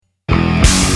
politically inspired punk-ska band
driving punk rock
Cuban influenced ska
snotty vocals